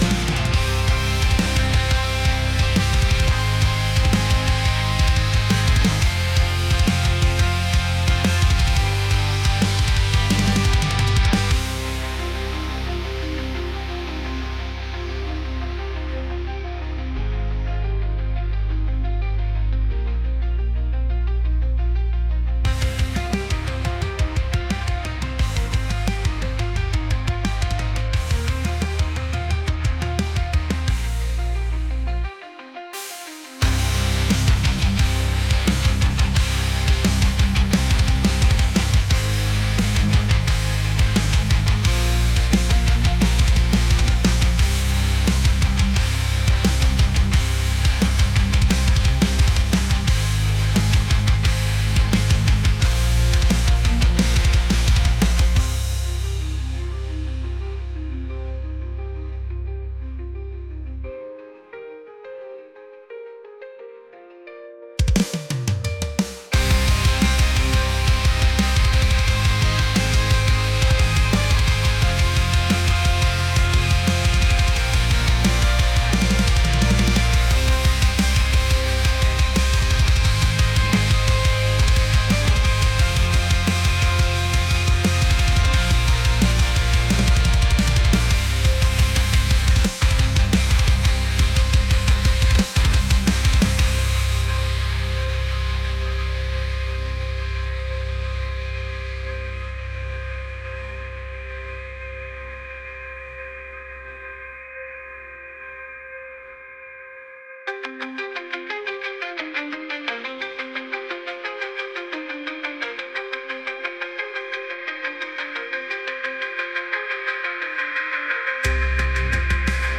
alternative | metal | heavy